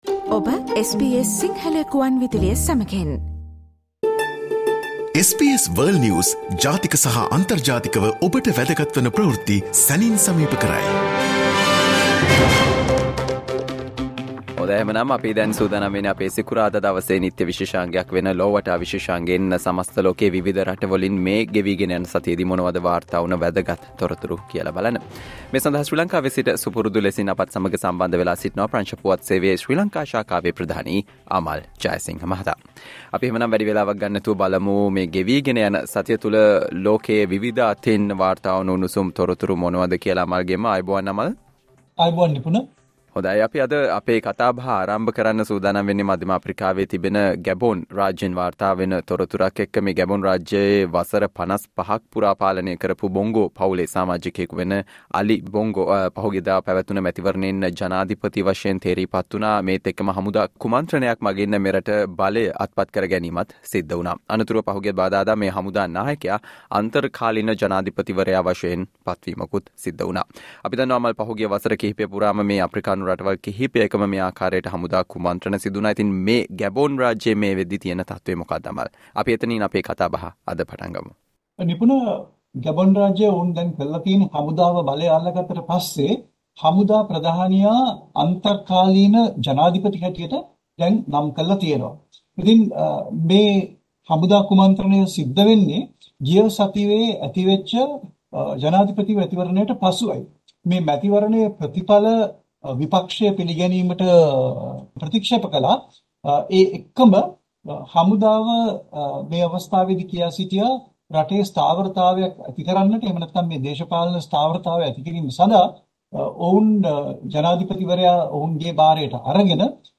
World's prominent news highlights in 12 minutes - listen to the SBS Sinhala Radio weekly world News wrap every Friday Share